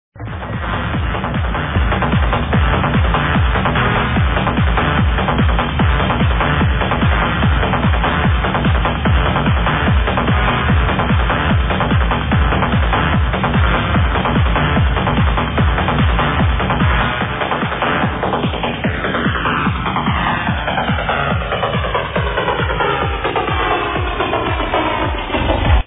from mayday 2001